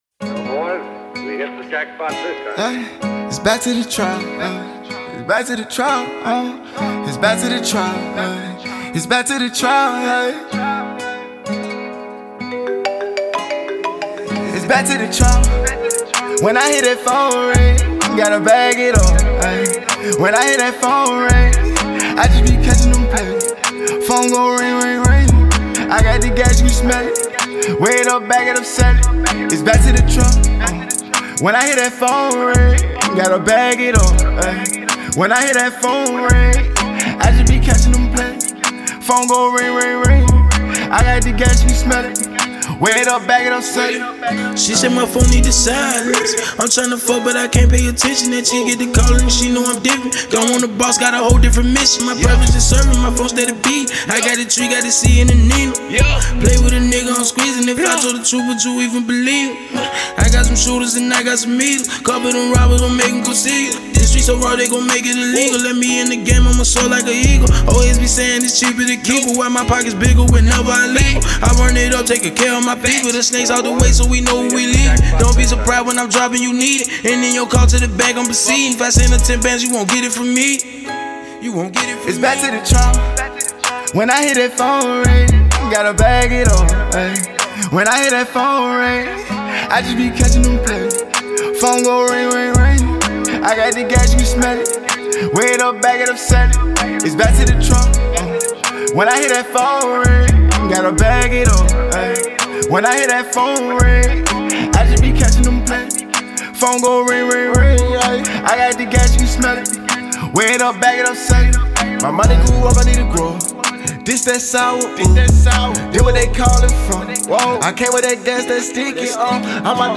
Hiphop
hop on a smooth beat and talk trappin